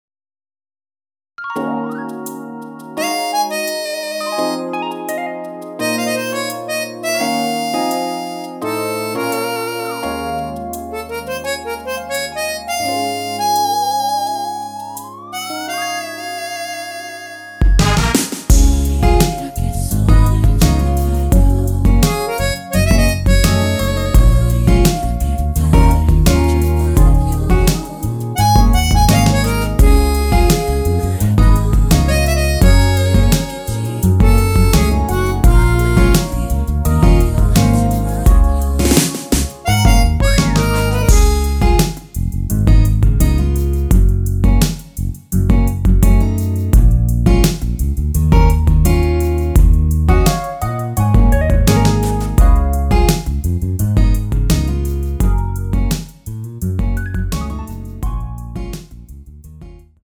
코러스 MR입니다.
랩은 코러스가 아니어서 포함되어있지 않습니다.
원키에서(-1)내린 코러스 포함된 MR입니다.
Db
앞부분30초, 뒷부분30초씩 편집해서 올려 드리고 있습니다.
중간에 음이 끈어지고 다시 나오는 이유는